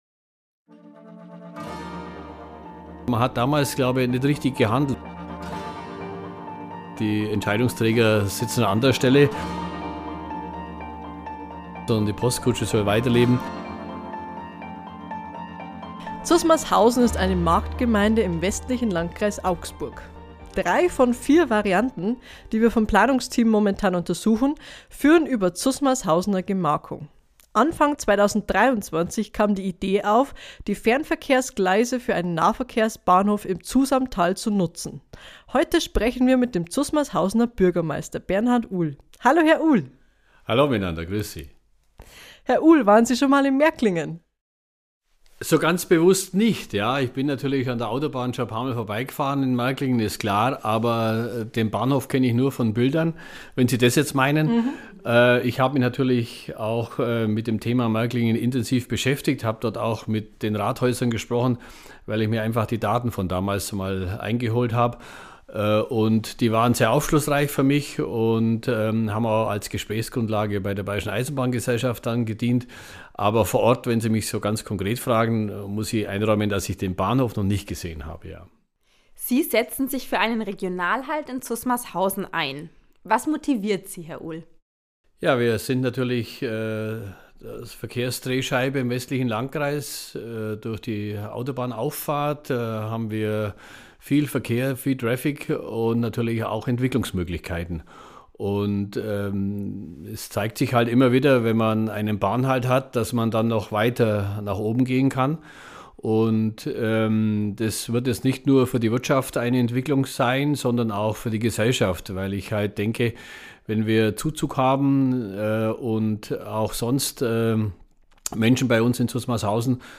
Ein Gespräch mit Bernhard Uhl...